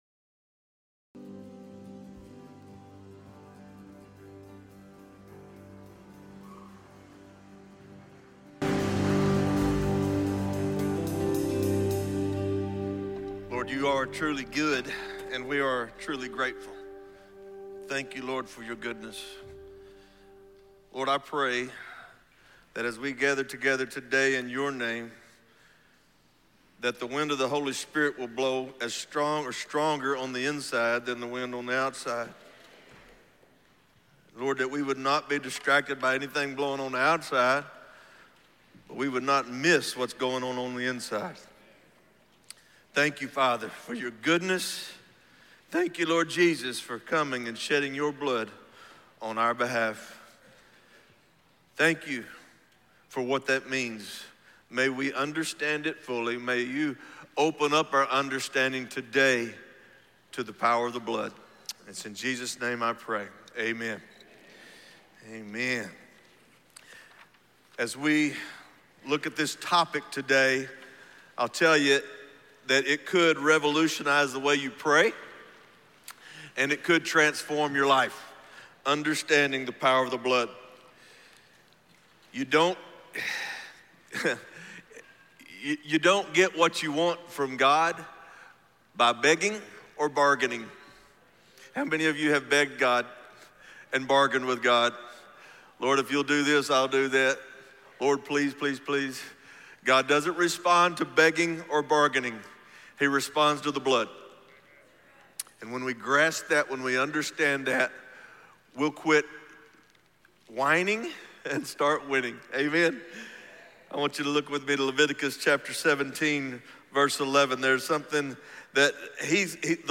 Weekly Inspiring messages and sermons to strengthen and encourage you.